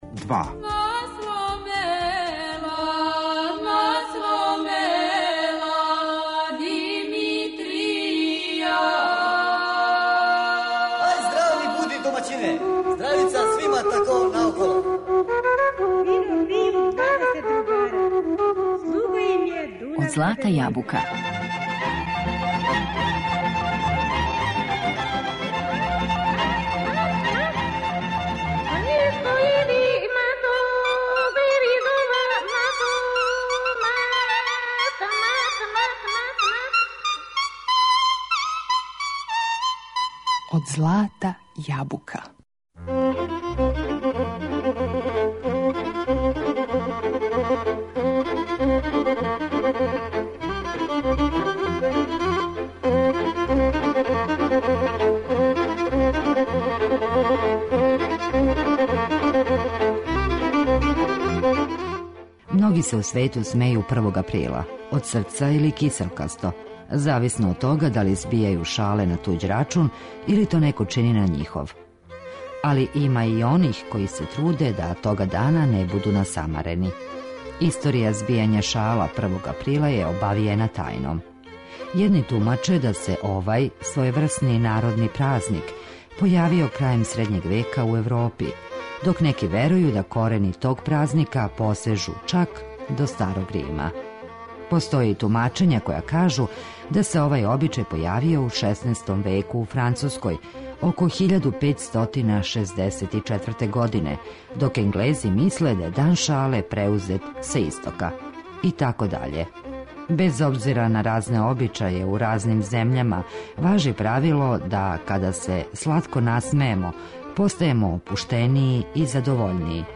Данас је први април, дан шале, па вам у данашњој емисији нудимо избор српских шаљивих прича које је сакупио Вук Стефановић Караџић, а слушаћемо и шаљиве народне песме, снимке из Тонског архива Радио Београда.